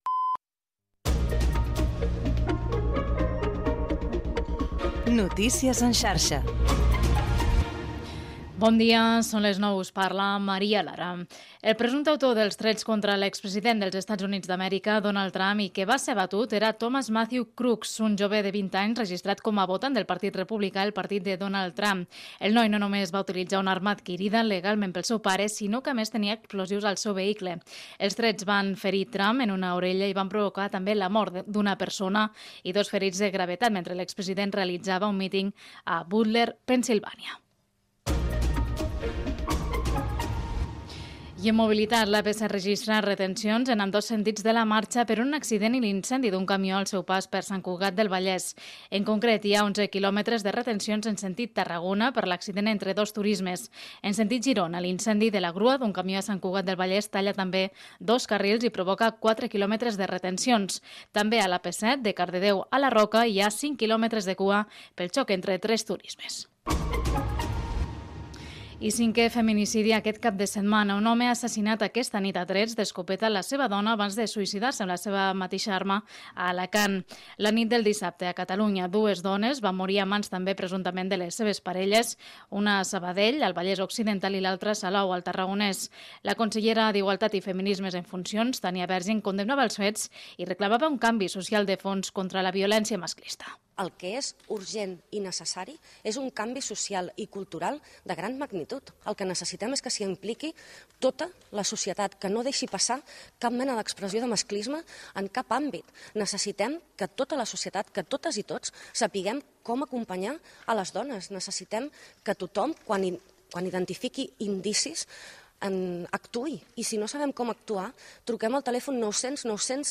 Butlletí de notícies de les 09:00. Autor dels trets contra Donald Trump en un míting a Pennsilvània, retencions a l'autopista AP-7, víctimes de violència de gènere a Catalunya, esports
Informatiu